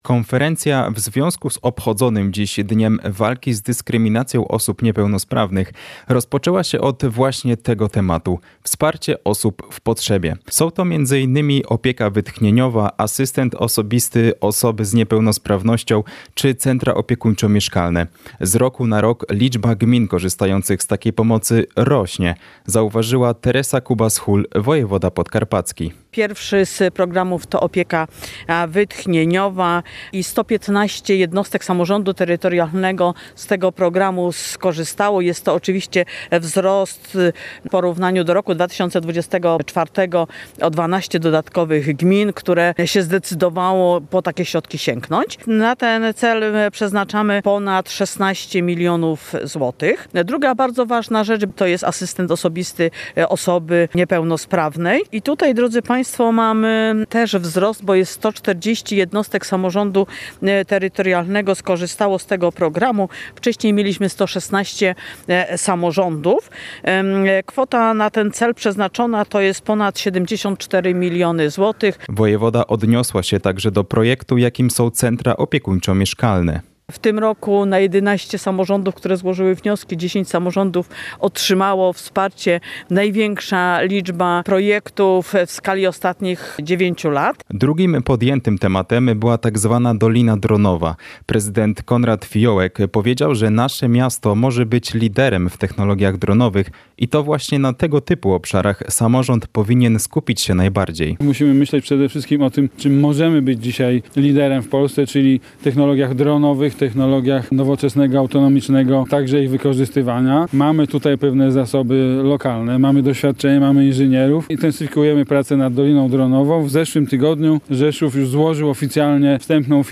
– powiedział na dzisiejszej (5.05) konferencji prasowej prezydent Rzeszowa Konrad Fijołek.